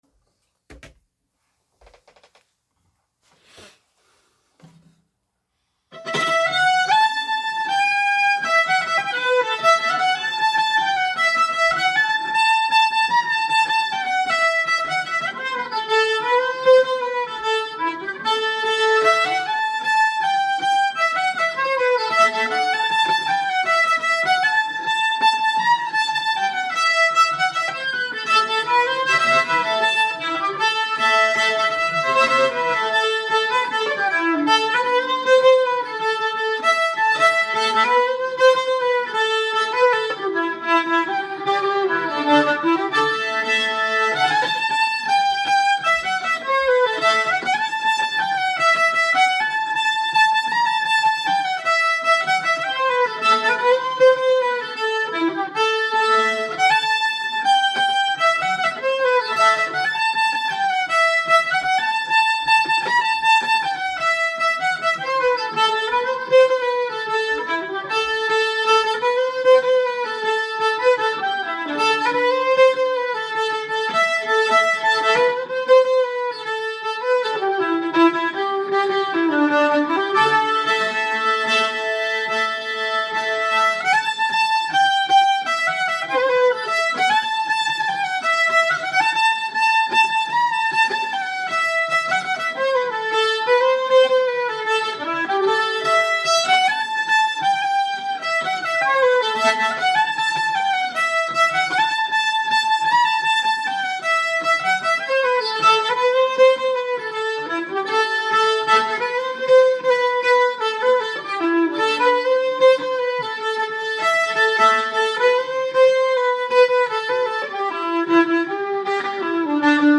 Newest 100 Songs fiddle songs which Fiddle Hangout members have uploaded to the website.